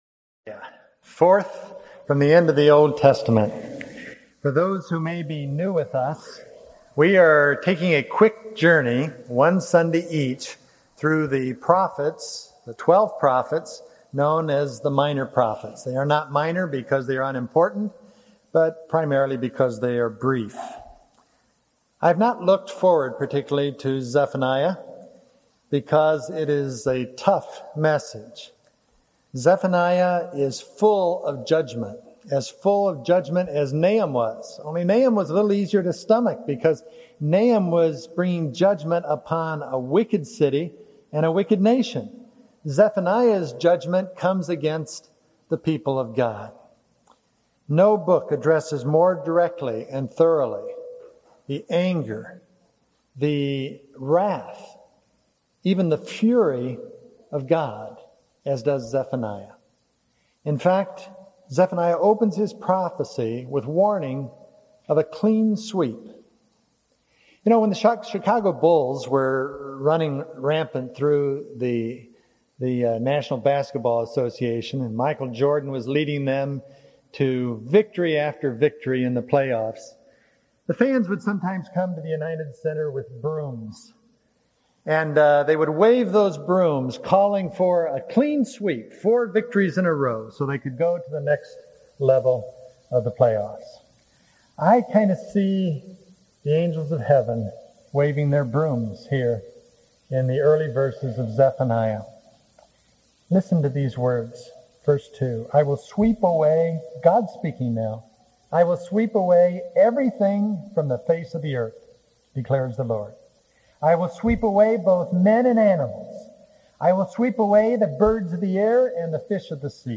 Note: This series, done in the summer of 1999, involved one sermon each on the Twelve Minor Prophets. Obviously, since these books are of varying lengths, from one chapter to fourteen chapters, these sermons are focused on the key message of each prophet, rather than a detailed examination of their words.